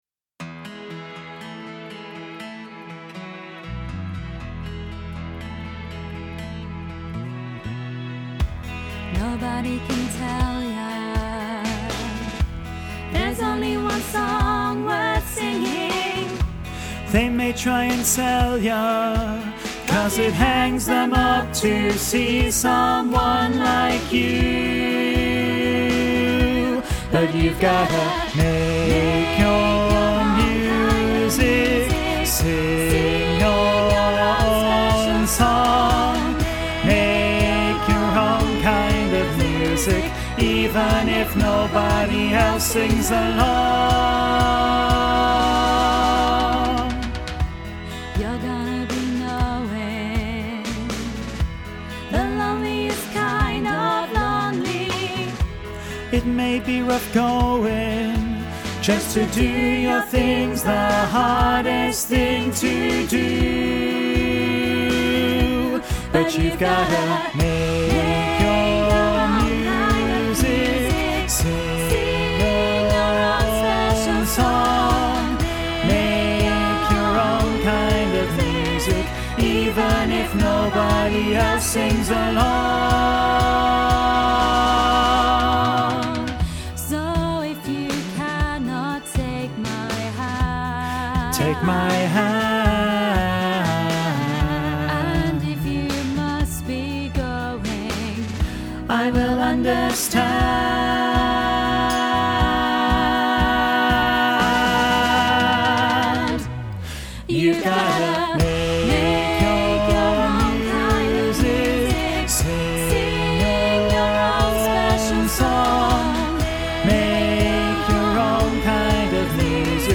Training Tracks
Listen to bass track with soprano and alto accompaniment